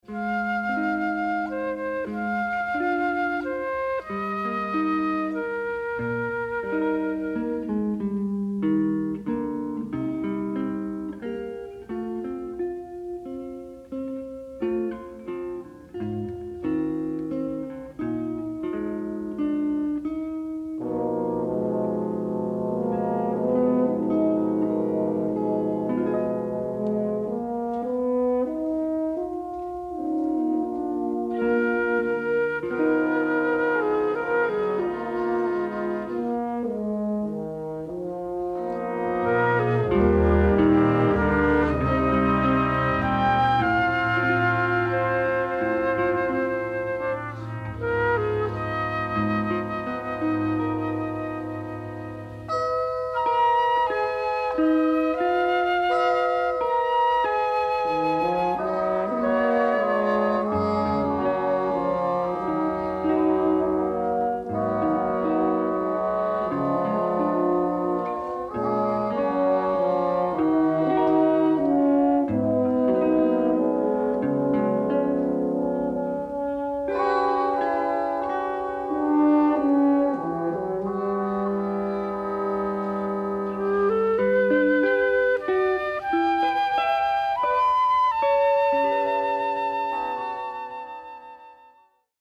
jazz-infused roller-coaster of a score